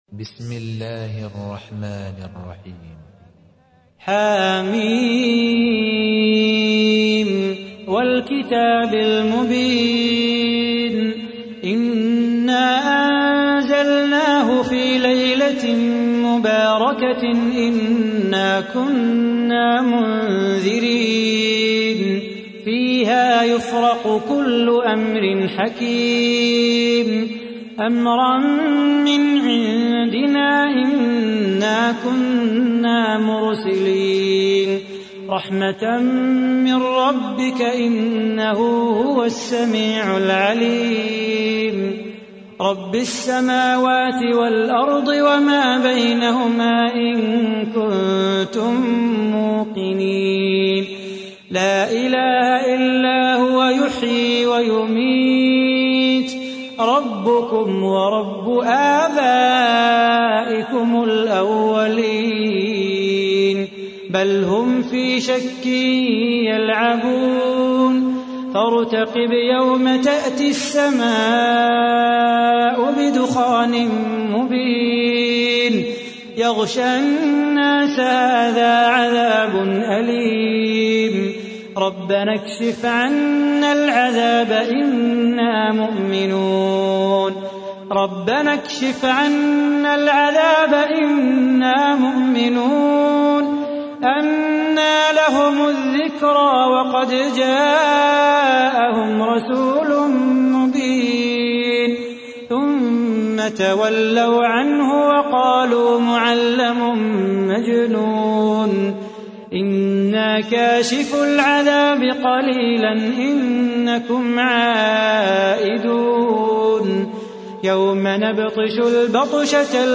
تحميل : 44. سورة الدخان / القارئ صلاح بو خاطر / القرآن الكريم / موقع يا حسين